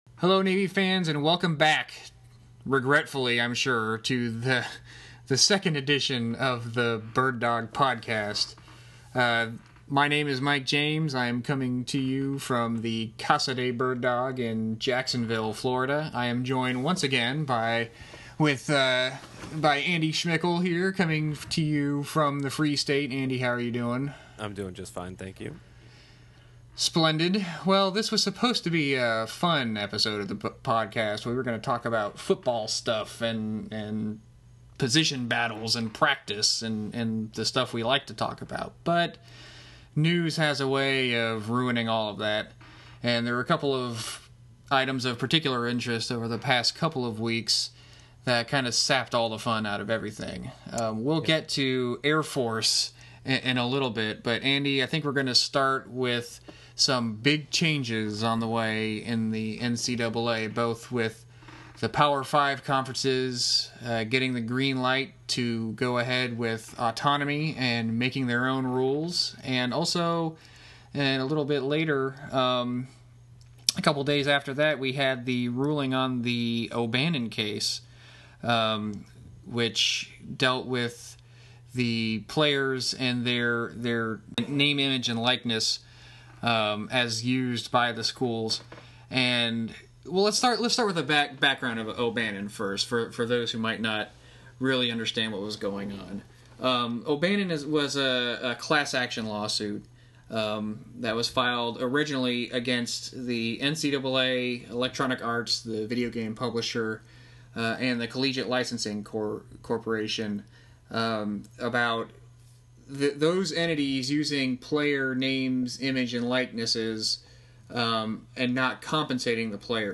This one is long and super glitchy but at least it starts on time. Topics this time around include NCAA changes thanks to O'Bannon and Power 5 autonomy, and Air Force's troubles.